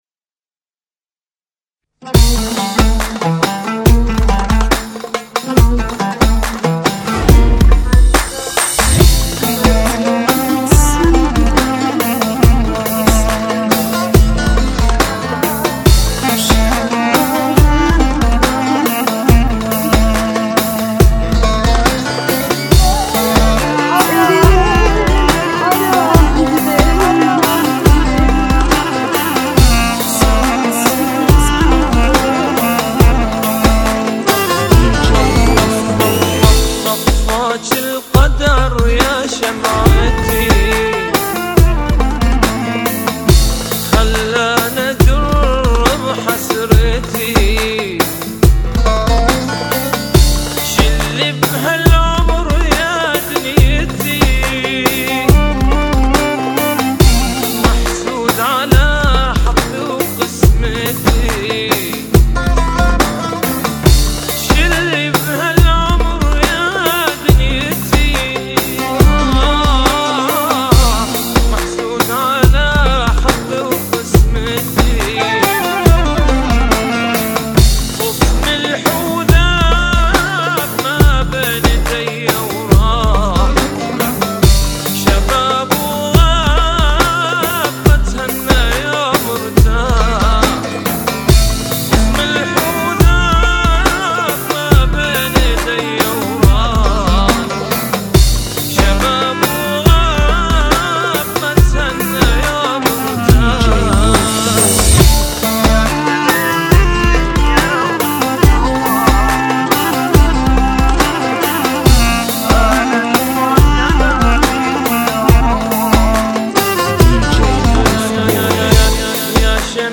EASY REMIX